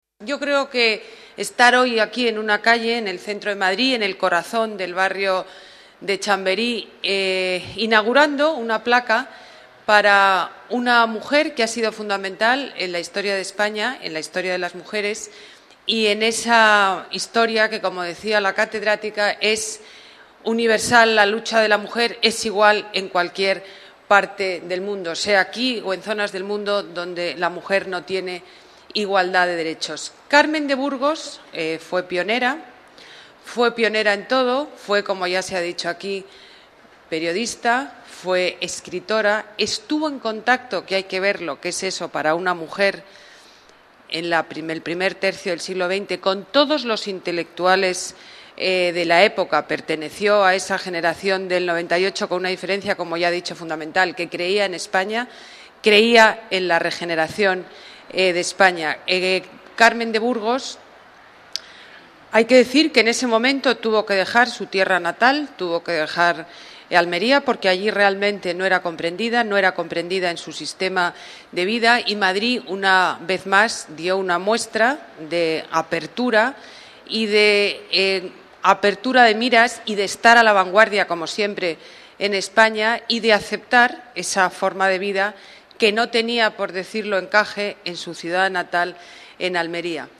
Nueva ventana:Ana Botella ensalza la figura de Carmen de Burgos, a la que el Ayuntamiento de Madrid ha dedicado una placa